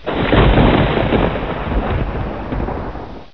Index of /pub/quakerepo/fortress/sound/ambience
thunder3.wav